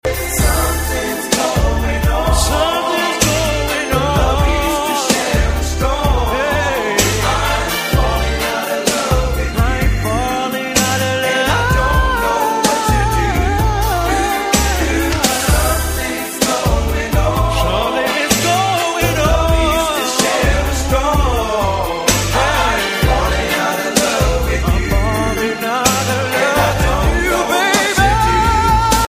RnB & Garage